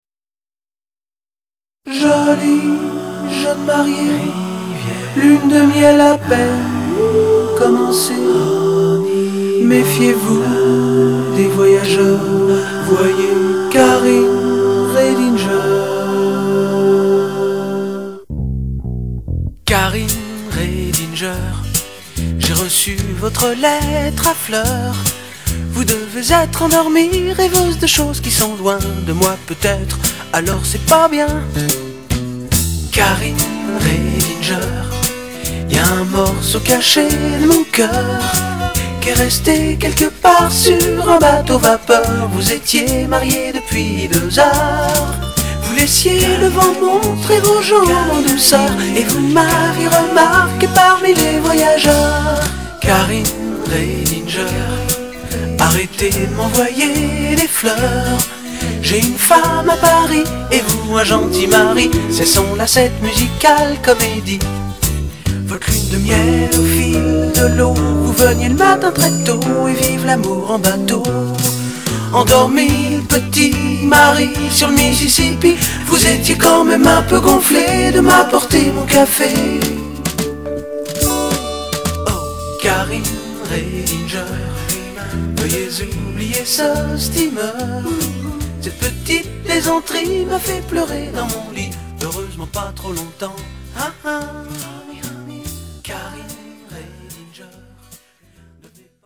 tonalité Sol majeur